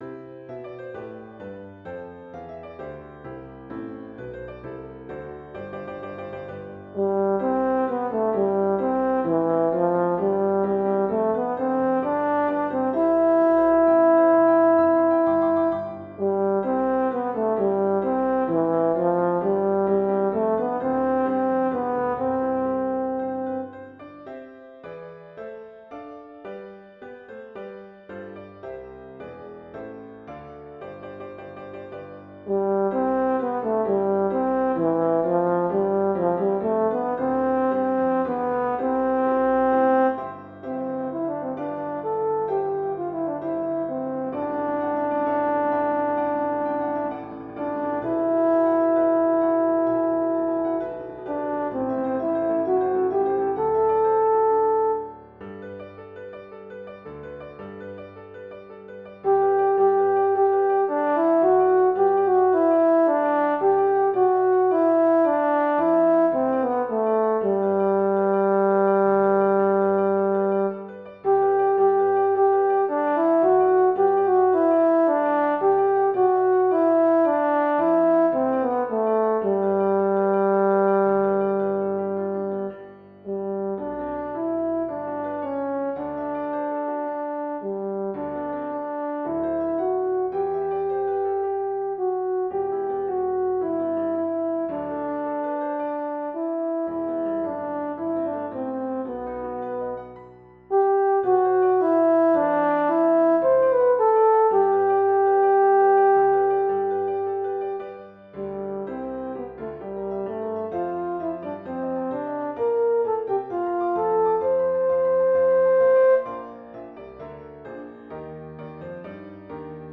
Intermediate Instrumental Solo with Piano Accompaniment.